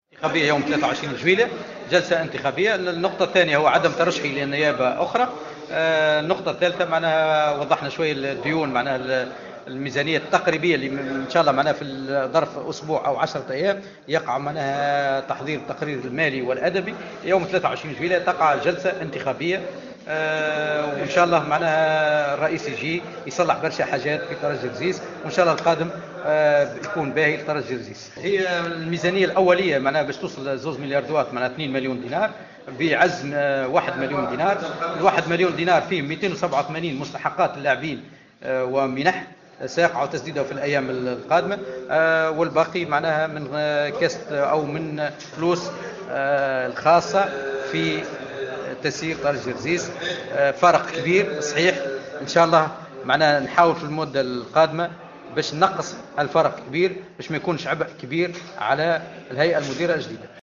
عقدت الهيئة المديرة لترجي الجرجيسي ليلة أمس الإربعاء ندوة صحفية للحديث حول...